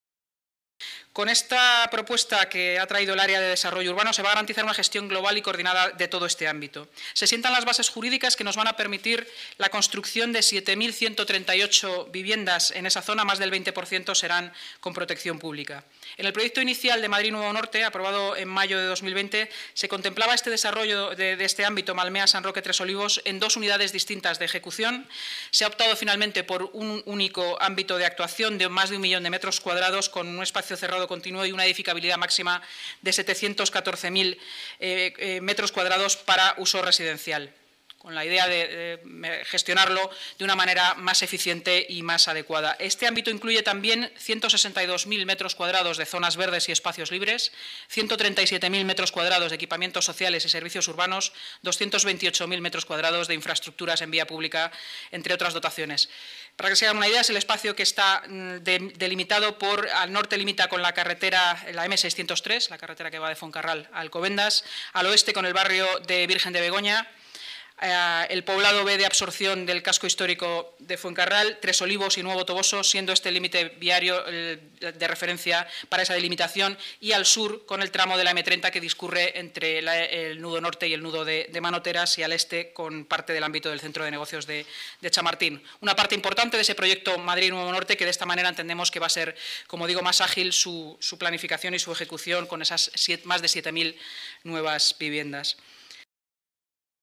Nueva ventana:Declaraciones de la delegada de Seguridad y Emergencias y portavoz municipal, Inmaculada Sanz, durante la rueda de prensa posterior a la Junta de Gobierno